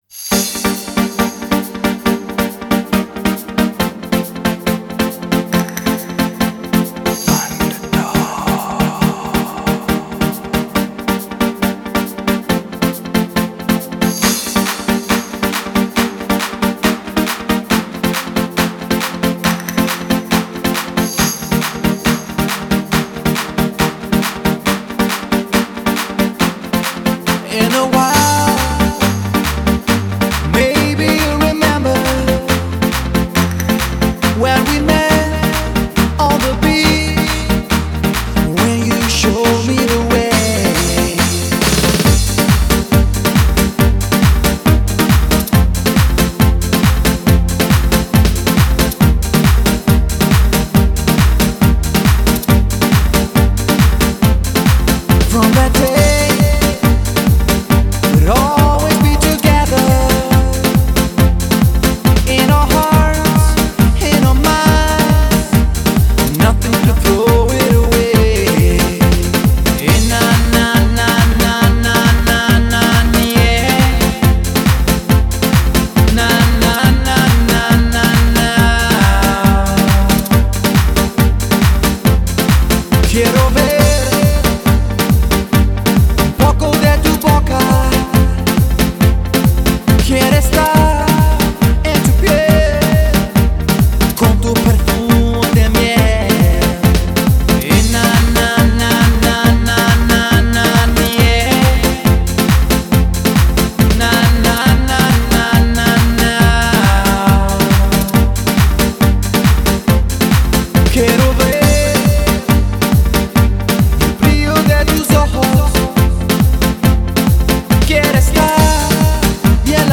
DJ舞曲，都是快节奏、劲爆的音乐。